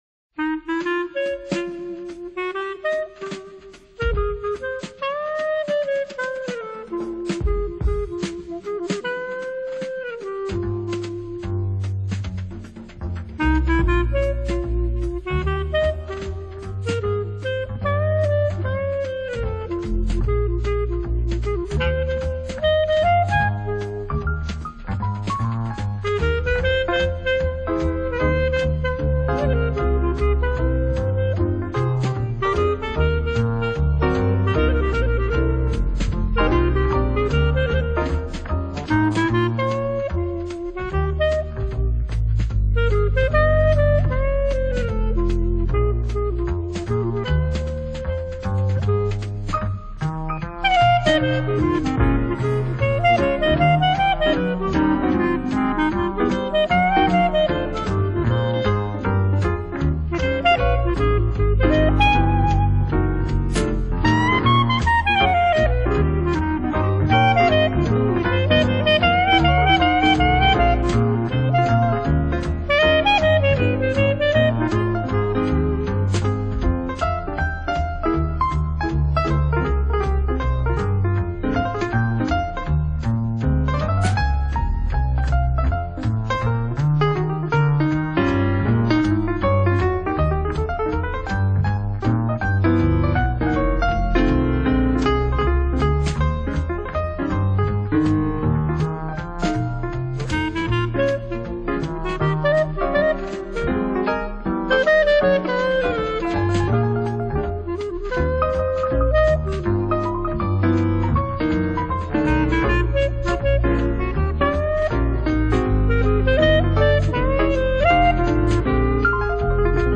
【爵士黑管】
Genre: Jazz, instrumental, clarinet
看單簧管的魔術，一定會讓你想開心舞蹈。